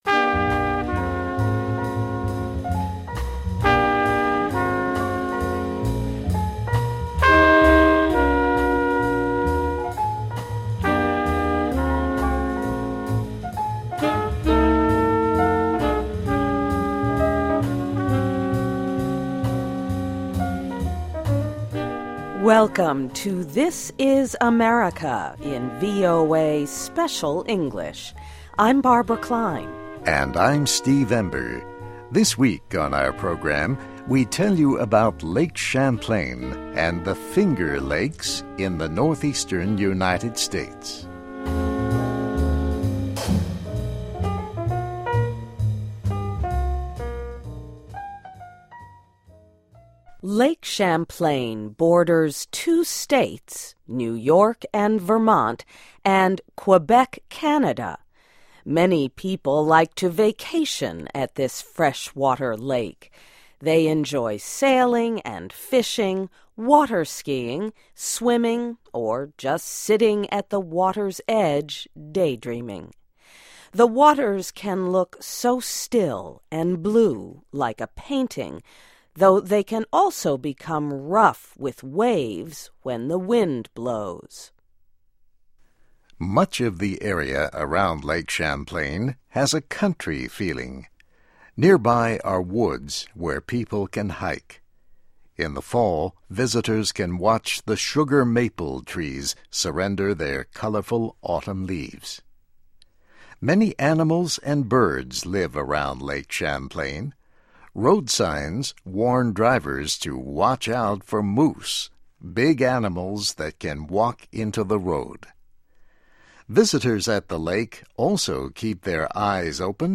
Welcome to THIS IS AMERICA in VOA Special English.